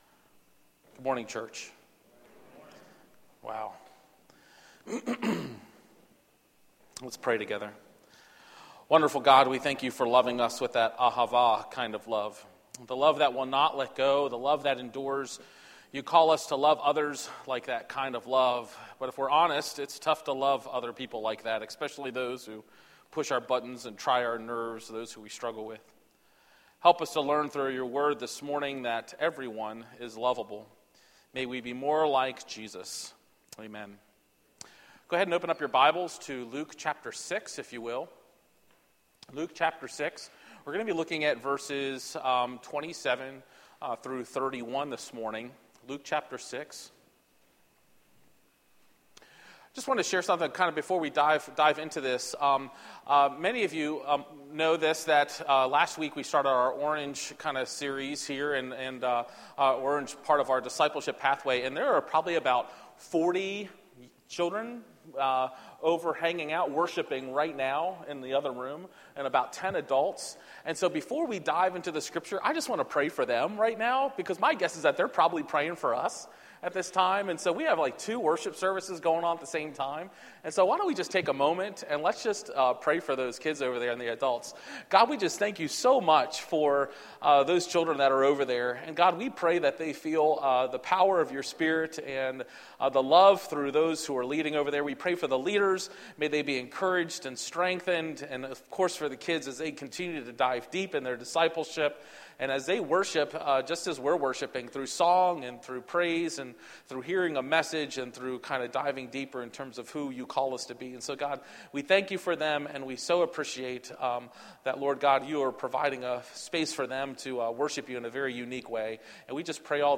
A message from the series "Love is Here ."